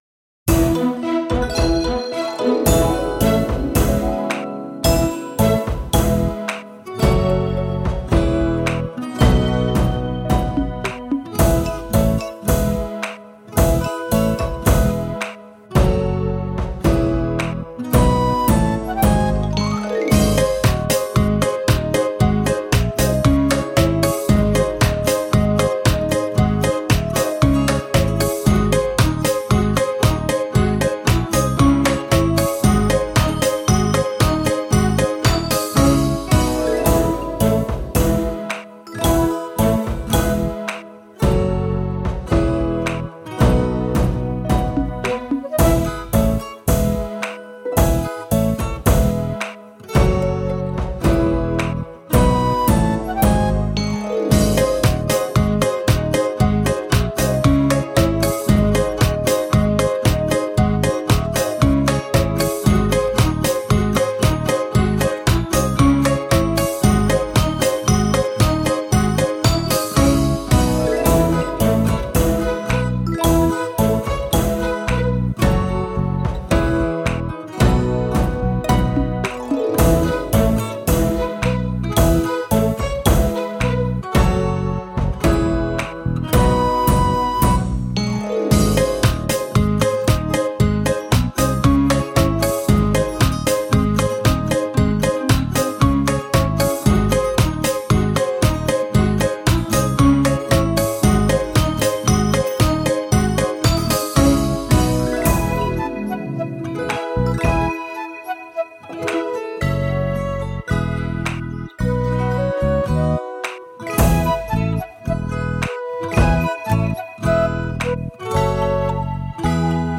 Здесь вы можете бесплатно скачать минусовку